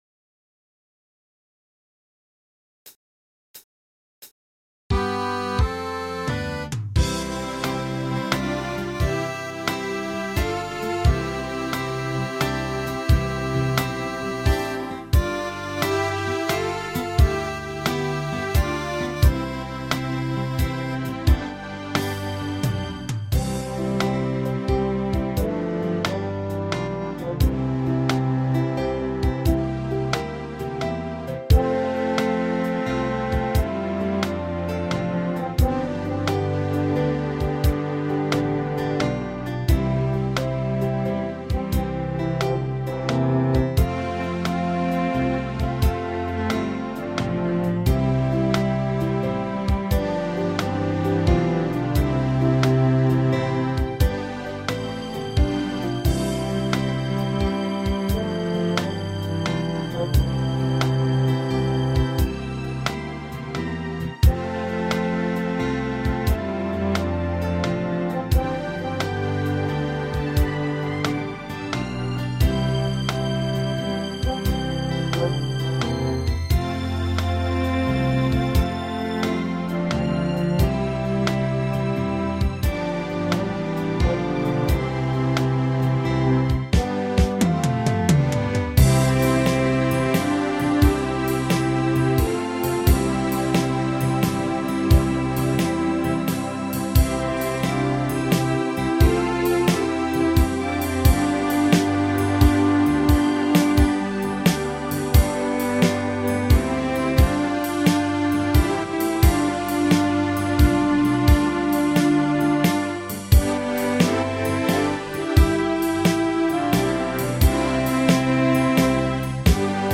PRO MIDI Karaoke INSTRUMENTAL VERSION
Alpenrock